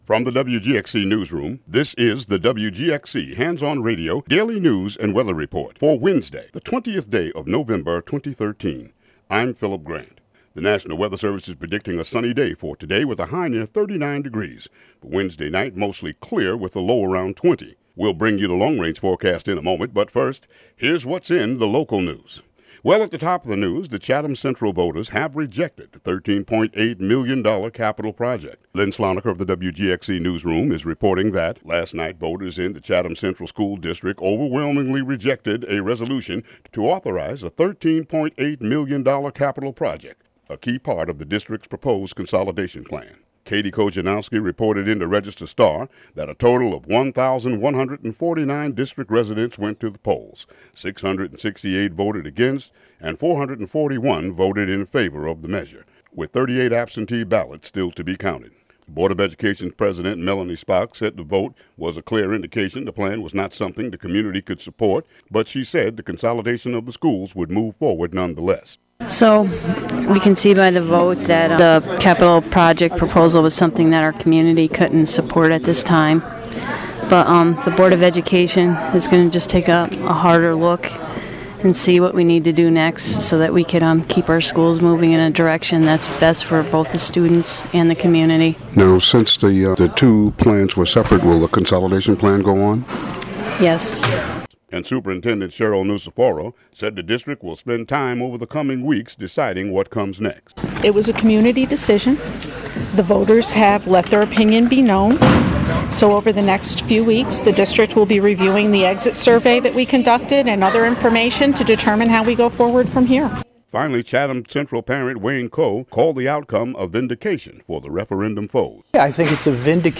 Local news and weather for Wednesday, November 20, 2013.